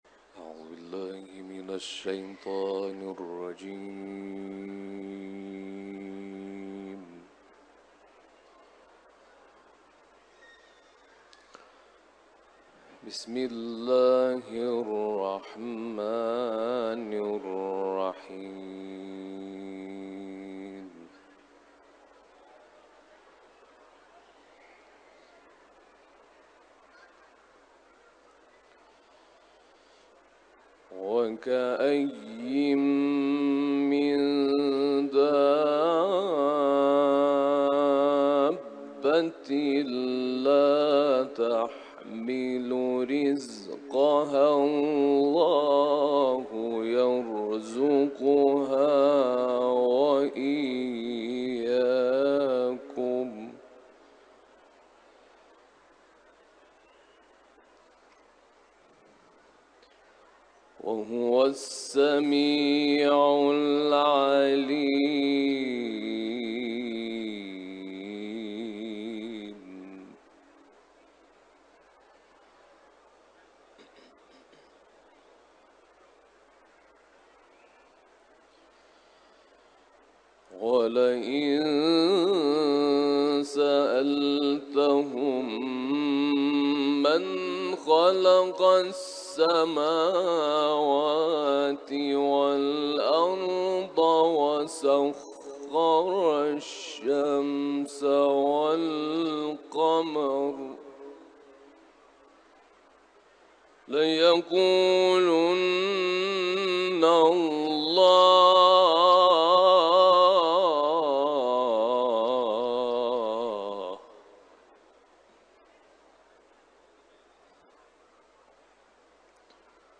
İranlı kâri Ankebut suresinden ayetler tilavet etti